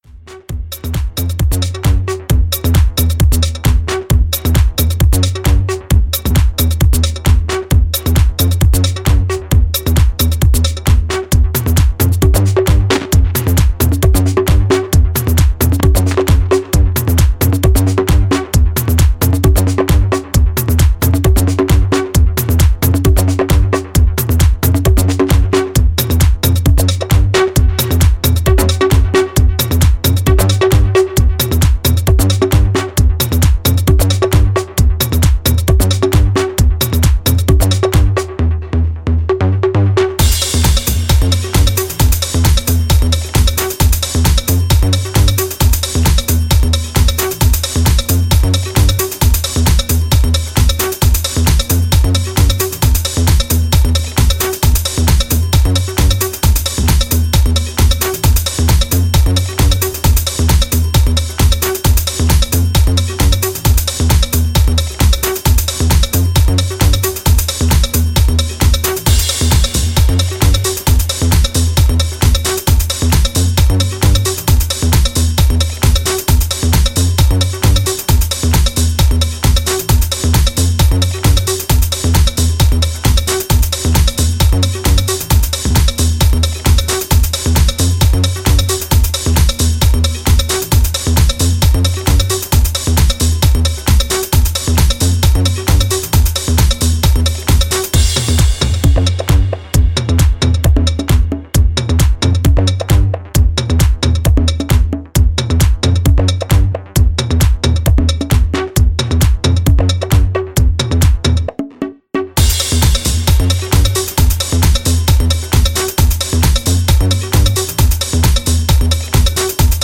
プロッギーなテイストのツールトラック路線のA面2曲もバッチリ即戦力。